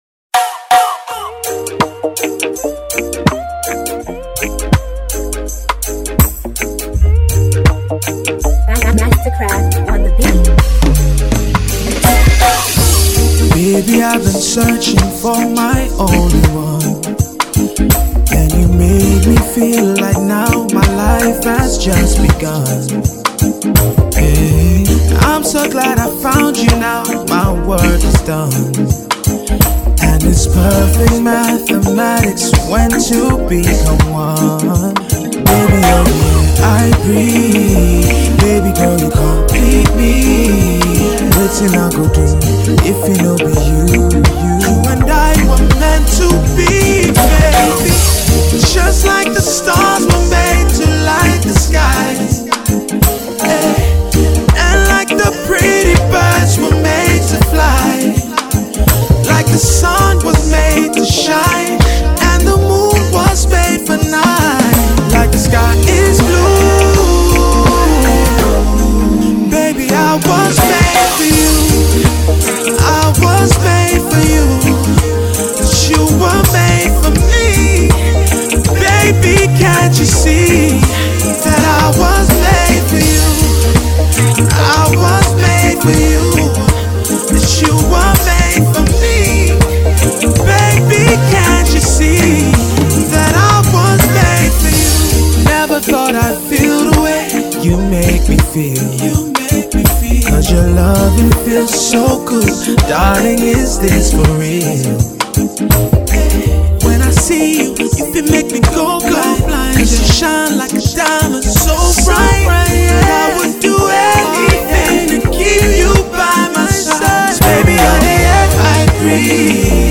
a love song with a reggae feel to it